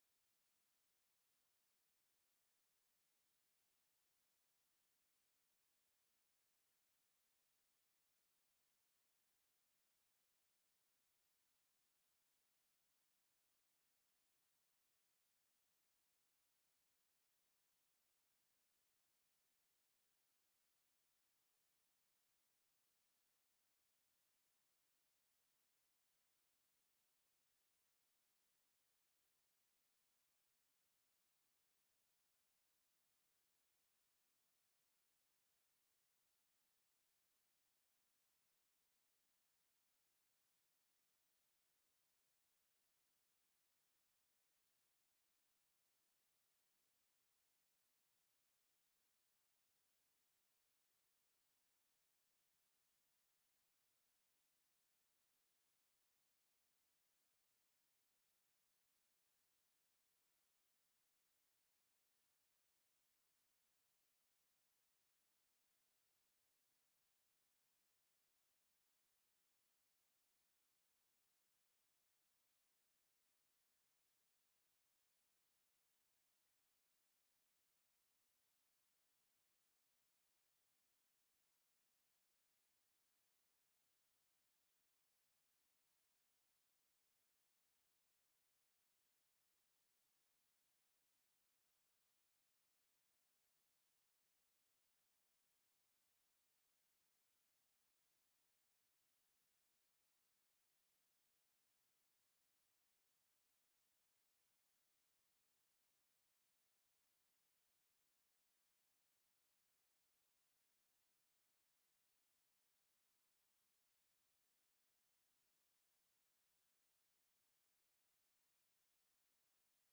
Acts 20:17-38 Service Type: Sunday Morning « The Eternality of God Wanna be Teachers of the Law?